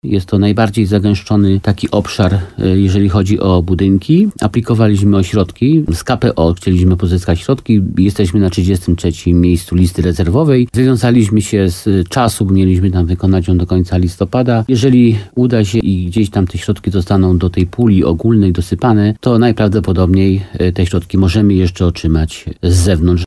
Jak powiedział w programie Słowo za Słowo w radiu RDN Nowy Sącz, wójt gminy Limanowa Jan Skrzekut, jej koszt wyniósł 4,5 mln złotych, ale jest szansa, że ta kwota się zwróci.